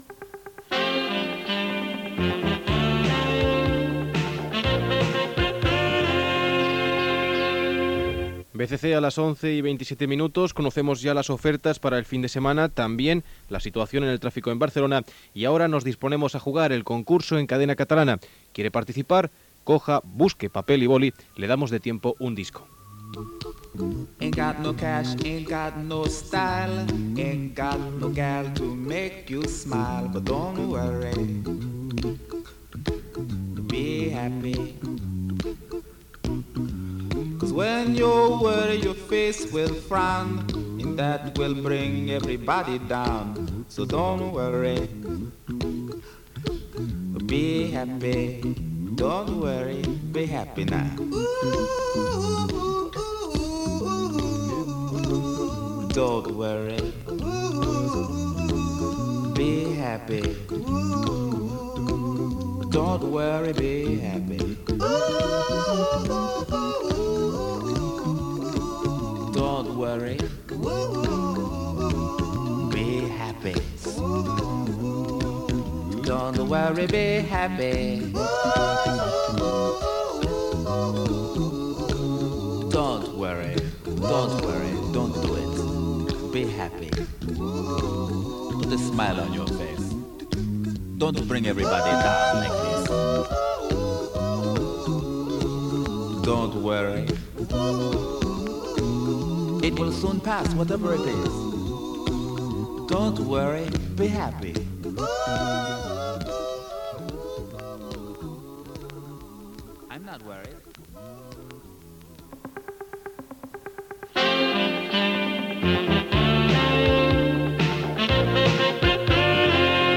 Gènere radiofònic Informatiu
Programa pilot de la radiofórmula Barcelona Cadena Catalana (BCC). Es tractava d'una proposta radiofònica informativa per a Cadena Catalana, a l'estil del que seria després France Info, Catalunya Informació o Radio 5 todo noticias.